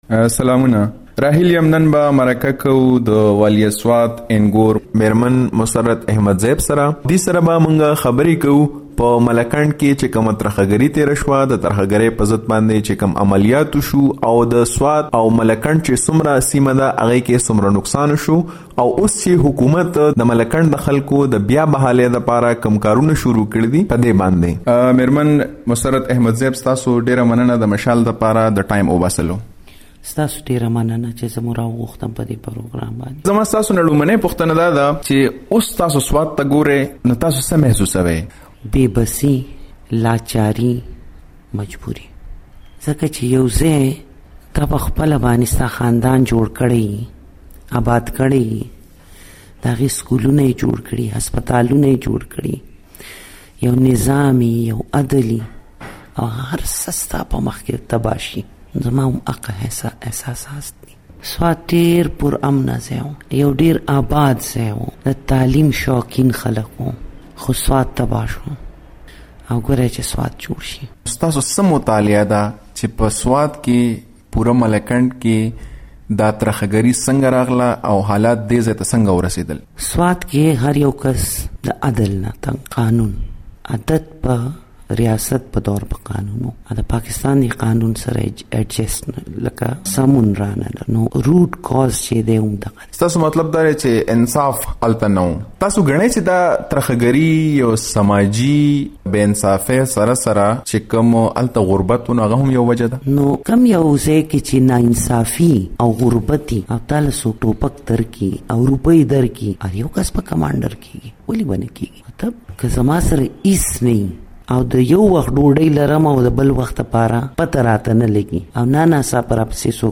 د سوات د پخواني والي میا ګل عبدالحق جهانزېب انږور مسرت احمدزېب سره د مشال مرکه